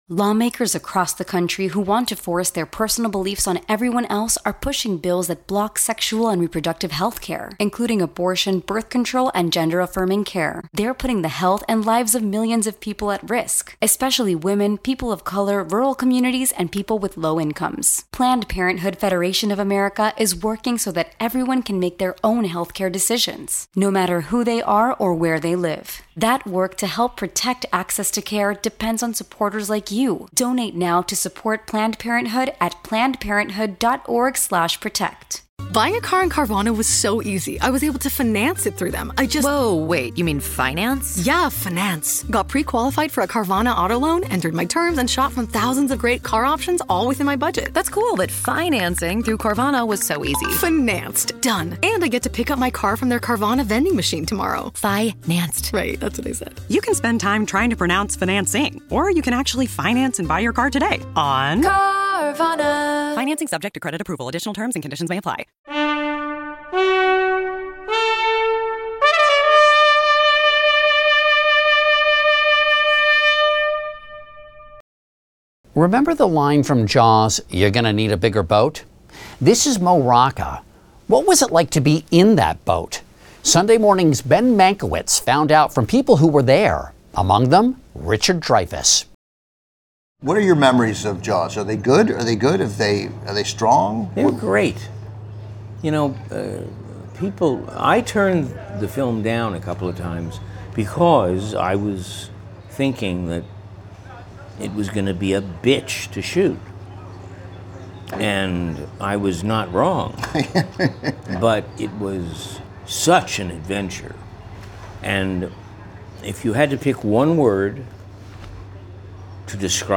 Extended Interview: Richard Dreyfuss on Filming "Jaws"
Actor Richard Dreyfuss talks with Turner Classic Movies host Ben Mankiewicz about fighting for the role that would launch his career into the stratosphere: the shark expert Matt Hooper in Steven Spielberg's "Jaws." He also discusses the notoriously problem-plagued production; his awe of co-star Robert Shaw; and the most valuable lesson he learned during the film's 159-day shoot on Martha's Vineyard.